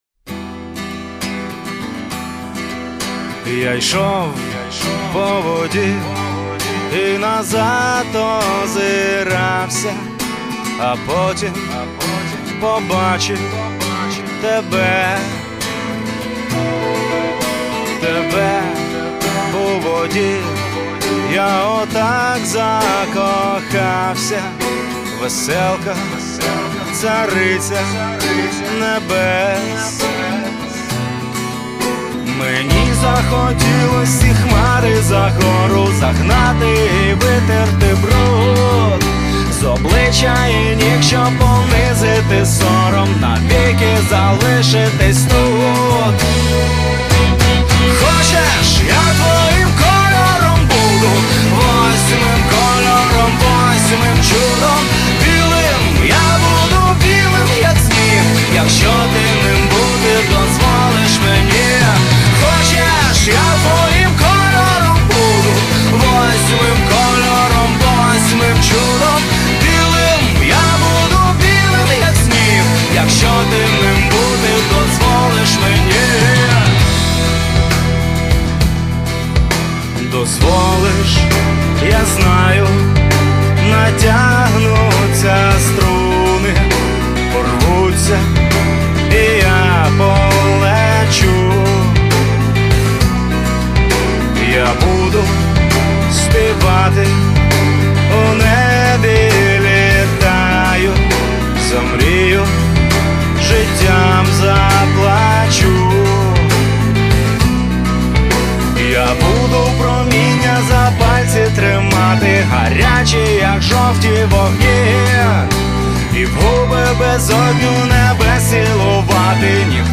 Категорія: Rock